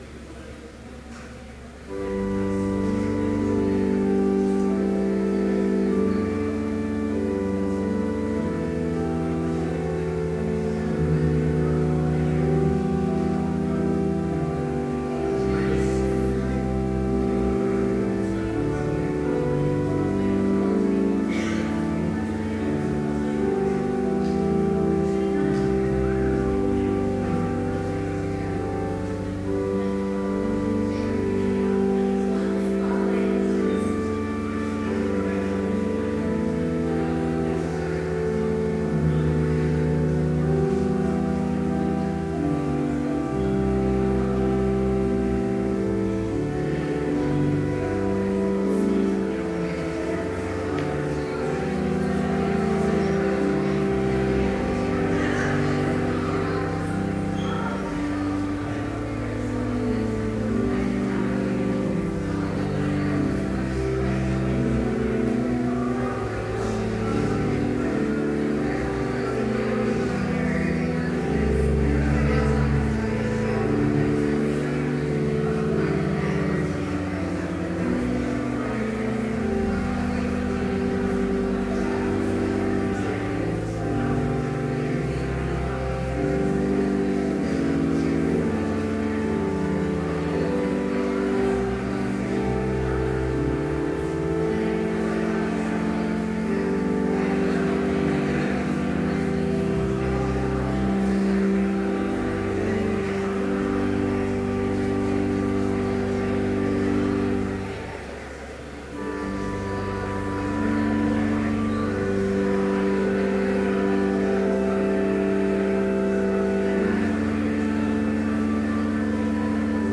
Complete service audio for Chapel - October 11, 2012